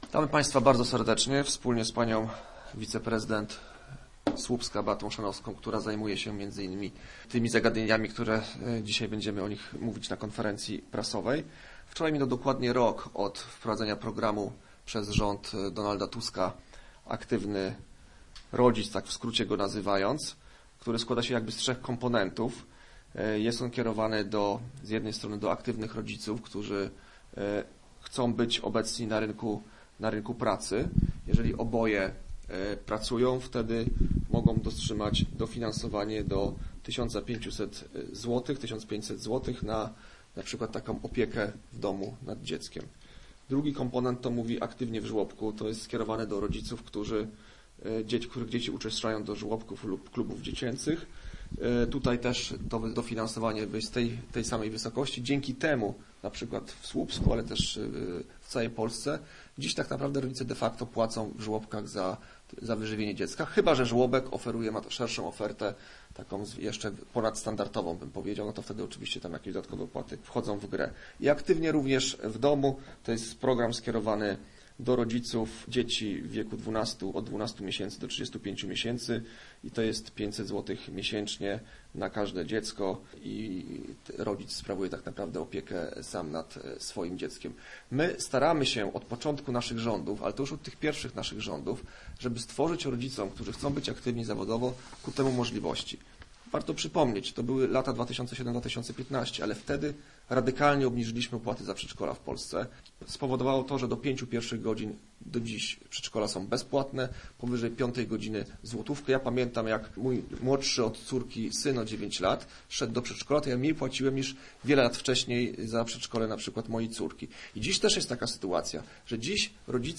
Program jest kierowany do aktywnych rodziców, którzy chcą być obecni na rynku pracy – mówił Zbigniew Konwiński, poseł Koalicji Obywatelskiej, podczas konferencji poświęconej podsumowaniu programu.
Jeszcze rok temu koszt żłobka był zdecydowanie wyższy – mówi Beata Chrzanowska, wiceprezydent Słupska.